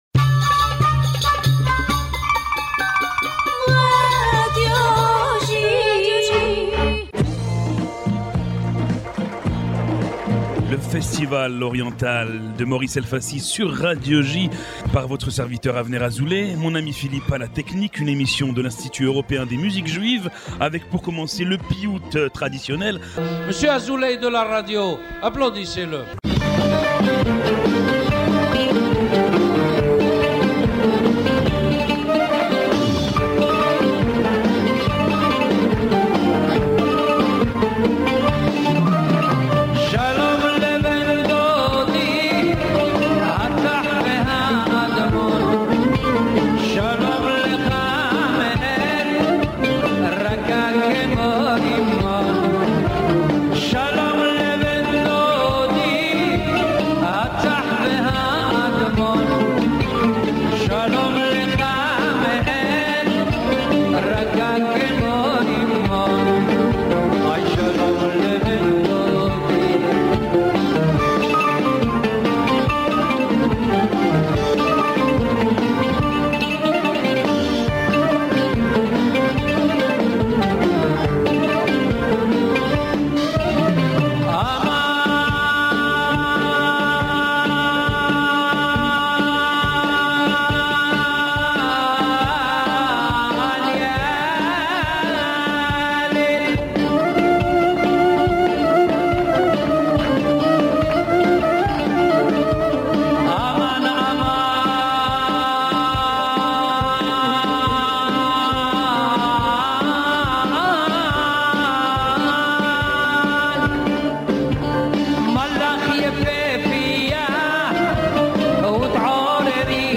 « The Oriental festival » is a radio program from the European Institute of Jewish Music entirely dedicated to Eastern Music.